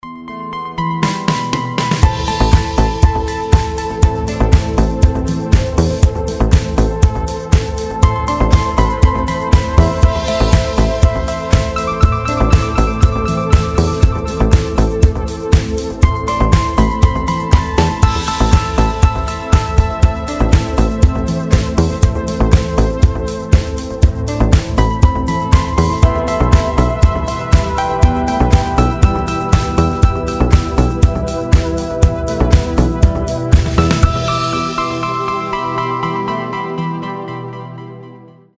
красивые
deep house
мелодичные
без слов
New Age